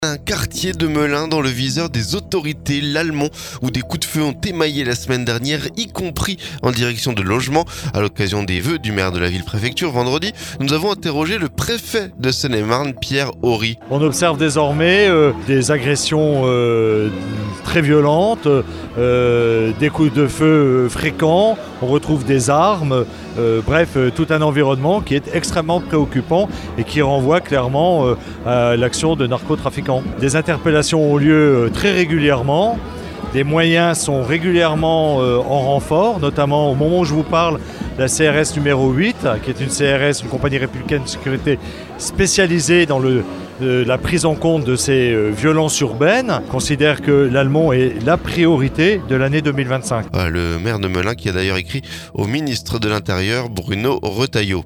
L'Almont, où des coups de feu ont émaillé la semaine dernière, y compris en direction de logements. A l'occasion des vœux du maire de la ville-préfecture vendredi, nous avons interrogé le préfet de Seine-et-Marne Piere Ory.